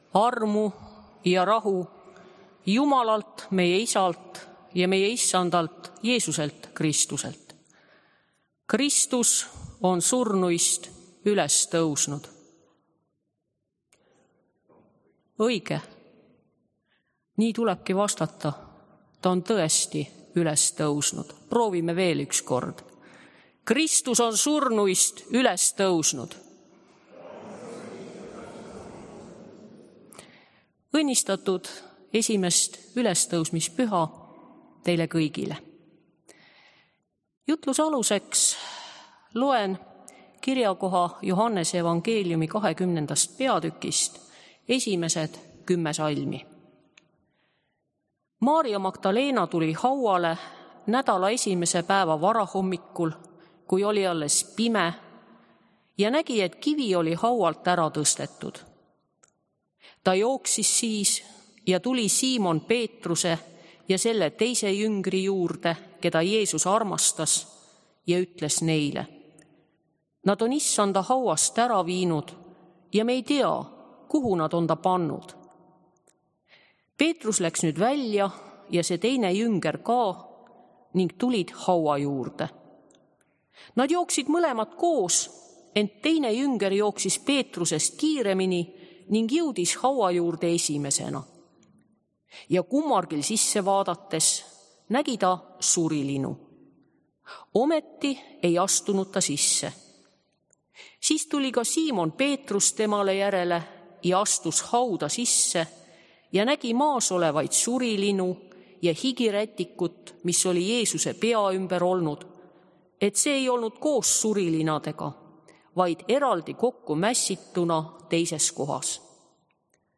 Jutlused ja kõned kirikus